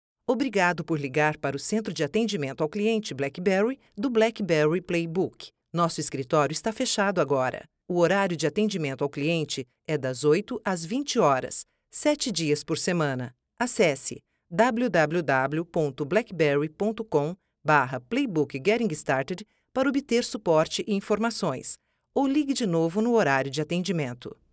Voiceover Portugues Brasileiro, voiceover talent.
Sprechprobe: Sonstiges (Muttersprache):